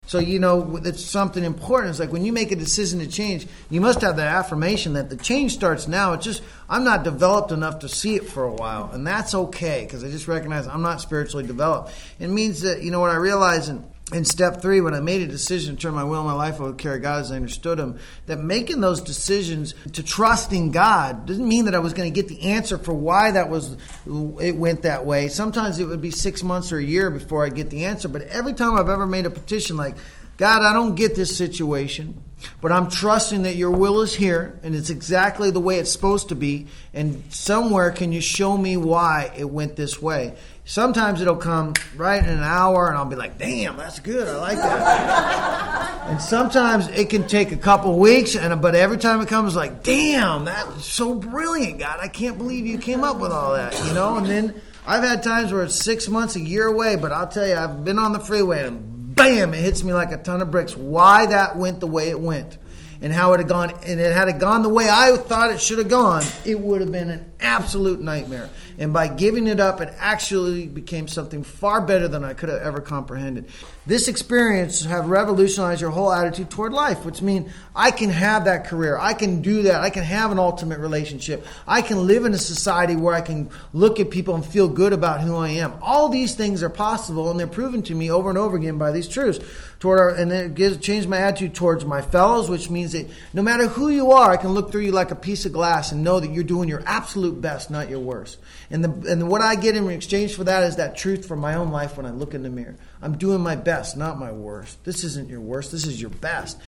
This audio archive is a compilation of many years of lecturing.